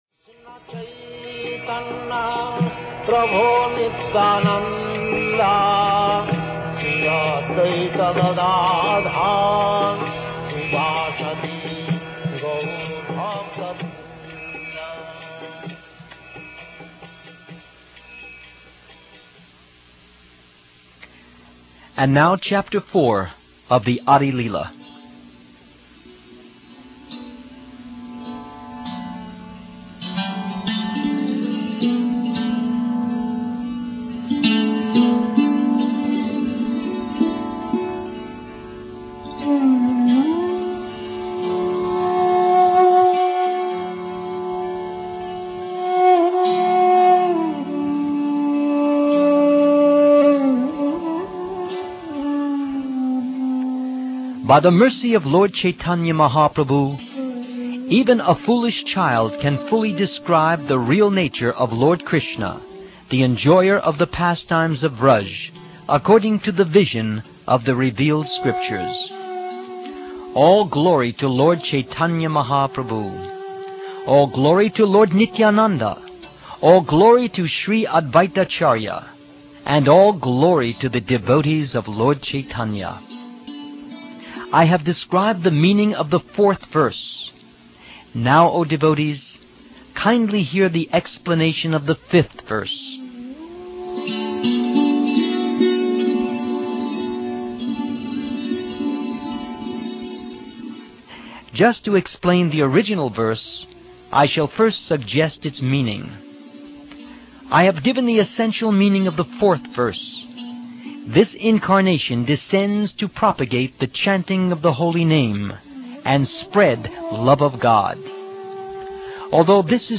Audio book.